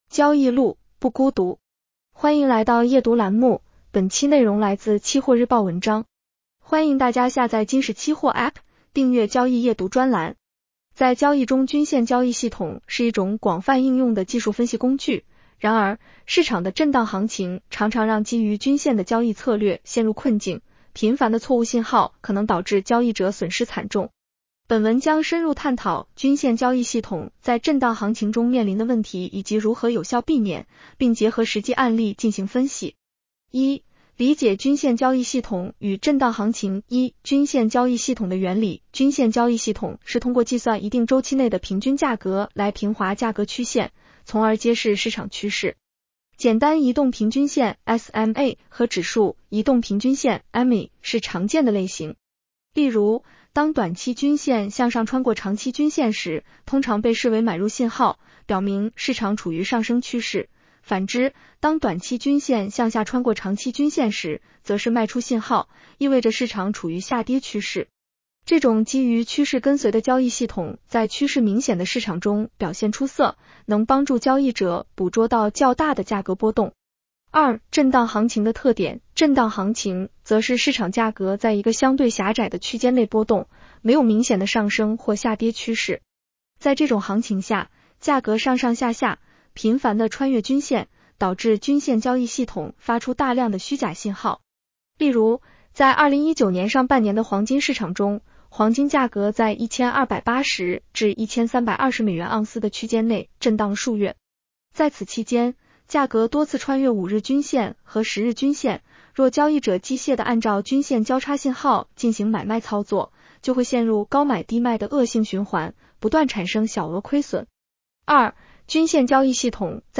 【期货交易夜读音频版】 女声普通话版 下载mp3 在交易中均线交易系统是一种广泛应用的技术分析工具，然而，市场的震荡行情常常让基于均线的交易策略陷入困境，频繁的错误信号可能导致交易者损失惨重。